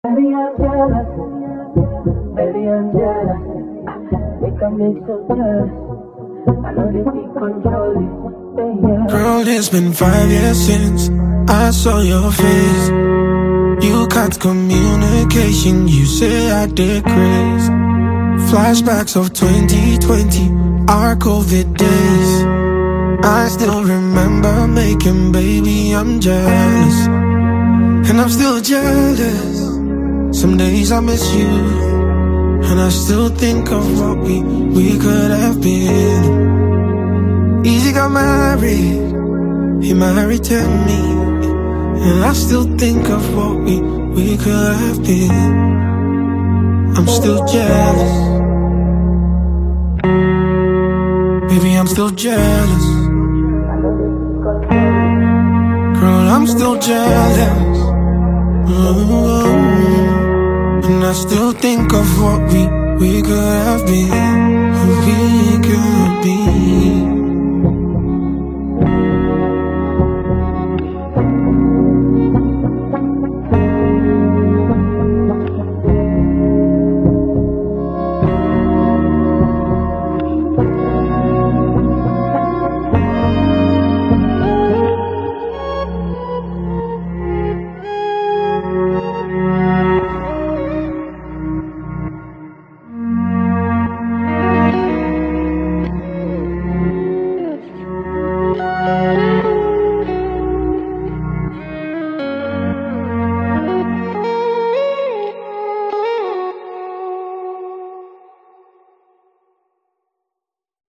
Soft Afrobeats rhythms
Soulful melodies
Clean, modern sound design